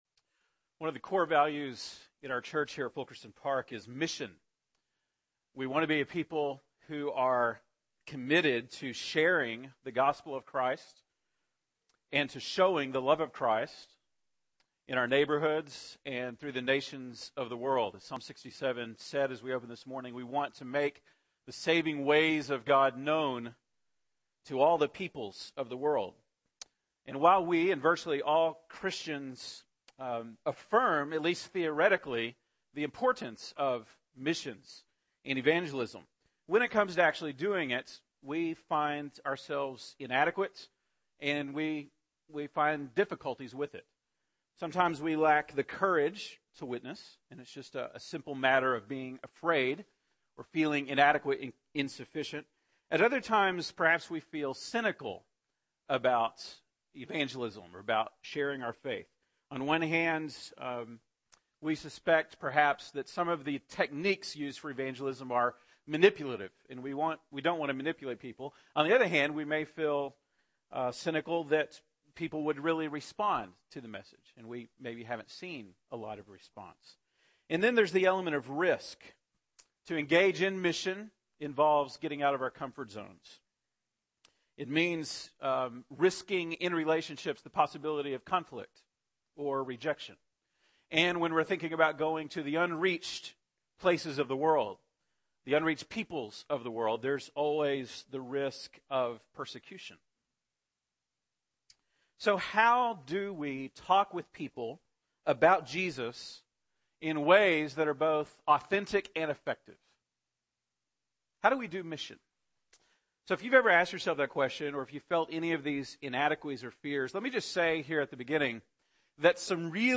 September 2, 2012 (Sunday Morning)